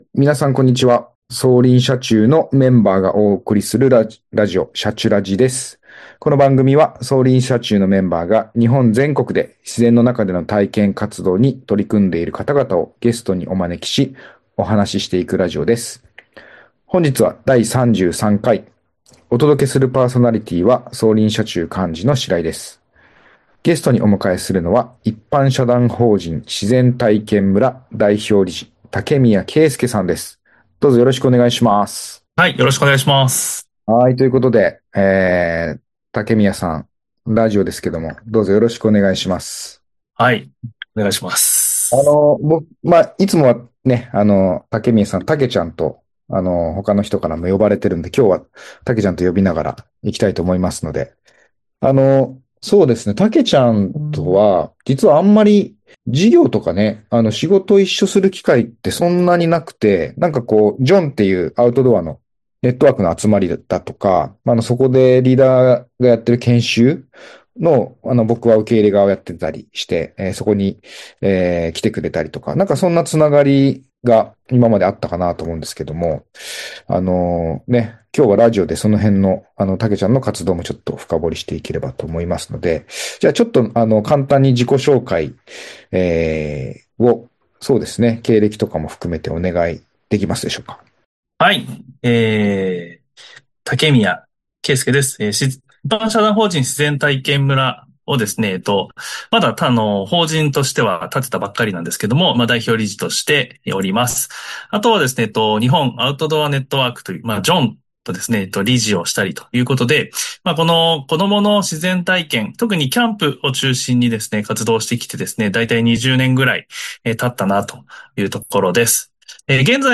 今回のゲストスピーカー
今回のパーソナリティ